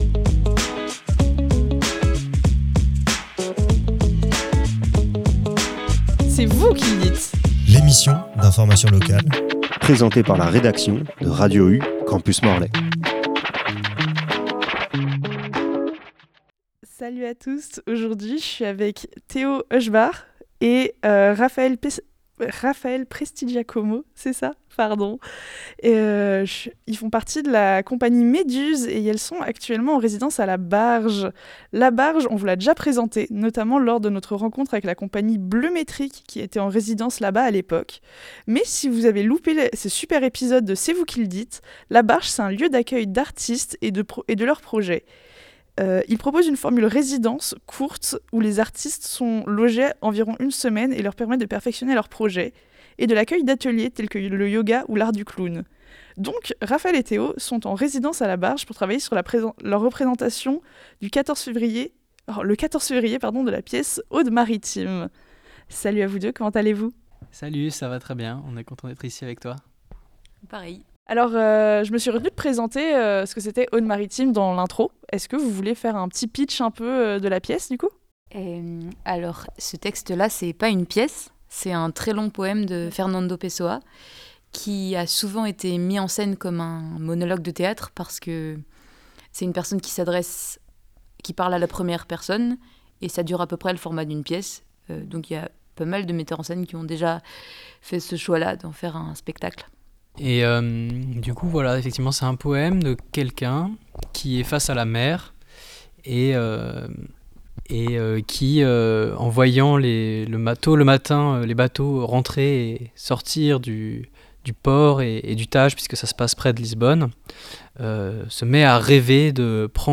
interview_ode_maritime_.mp3